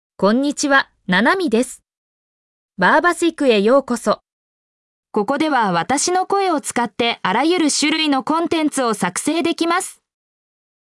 NanamiFemale Japanese AI voice
Nanami is a female AI voice for Japanese (Japan).
Voice sample
Listen to Nanami's female Japanese voice.
Female
Nanami delivers clear pronunciation with authentic Japan Japanese intonation, making your content sound professionally produced.